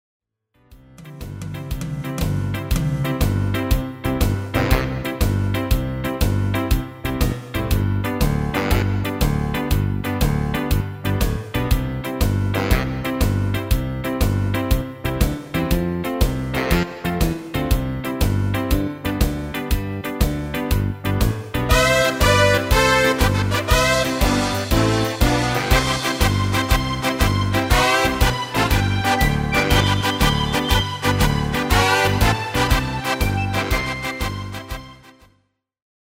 Demo/Koop midifile
Genre: Carnaval / Party / Apres Ski
- Géén vocal harmony tracks